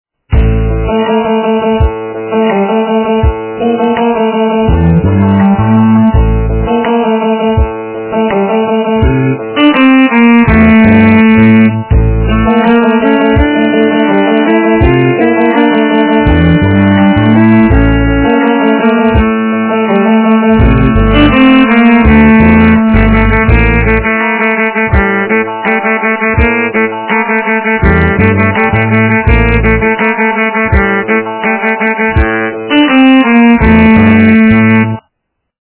- рэп, техно